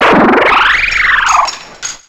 Cri de Cliticlic dans Pokémon X et Y.